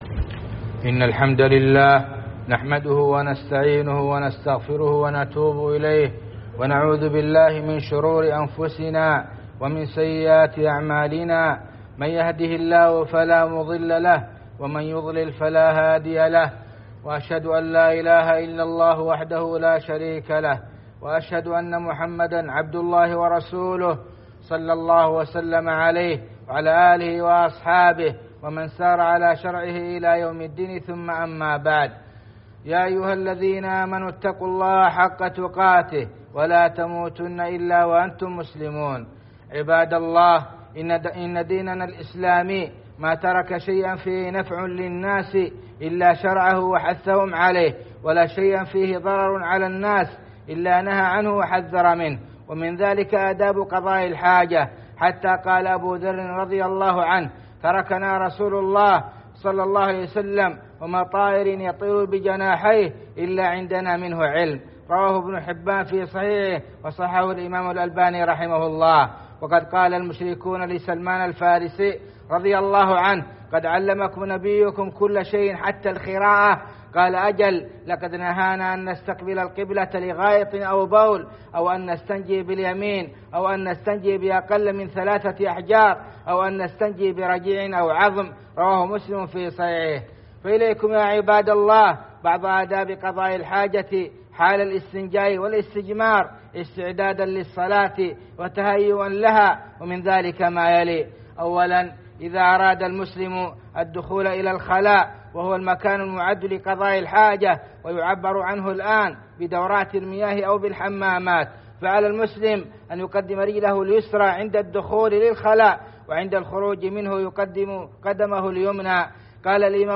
خطب الجمعة والأعياد